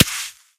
6f19f2c70f Divergent / mods / JSRS Sound Mod / gamedata / sounds / material / bullet / collide / sand03gr.ogg 18 KiB (Stored with Git LFS) Raw History